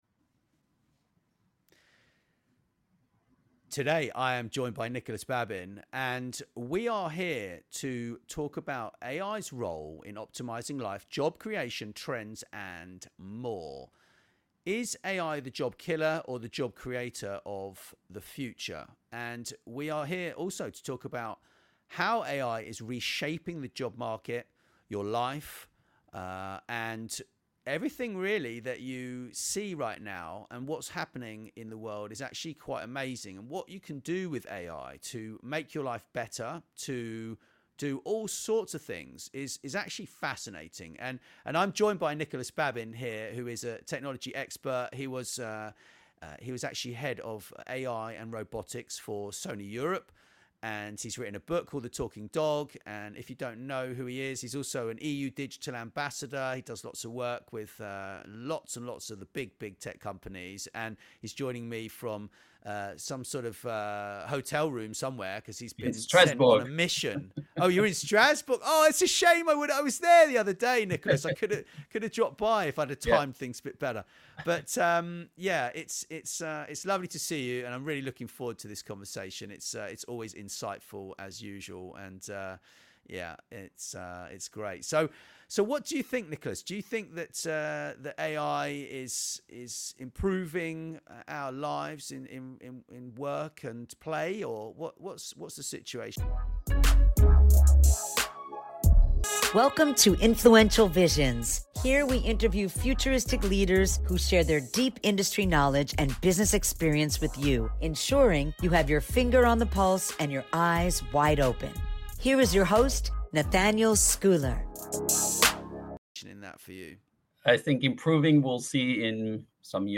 We have another exceptional interview